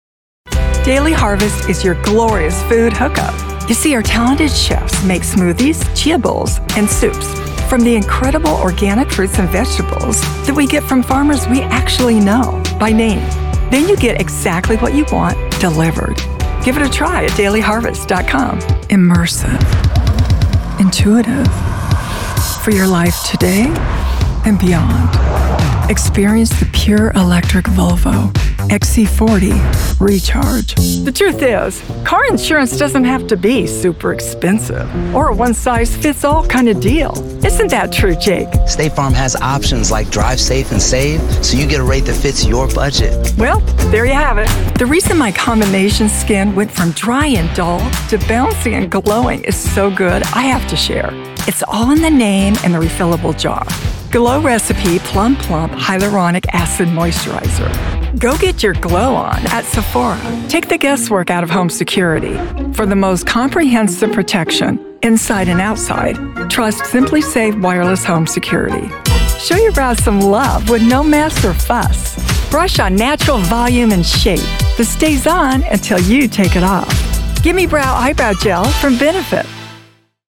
Voice actor and on-camera.